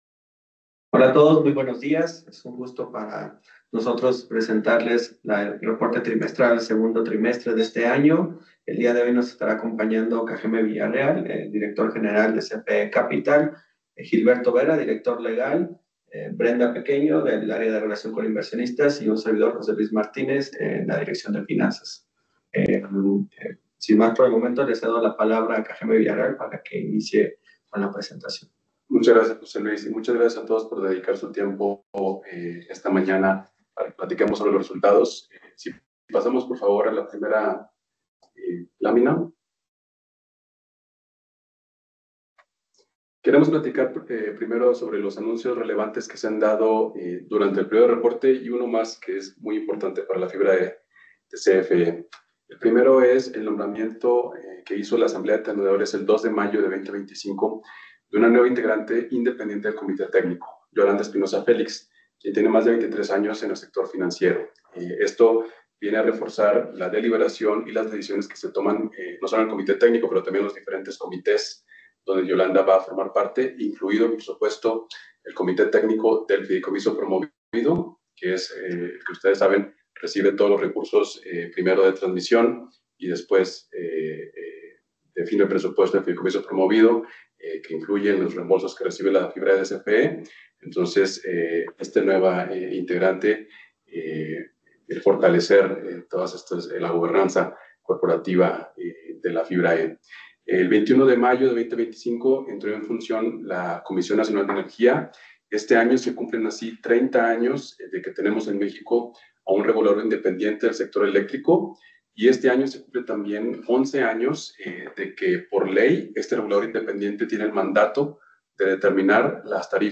2T25 Presentación llamada con inversionistas CFE FIBRA E - CFE Capital
Llamada-con-inversionistas-2T25-1.mp3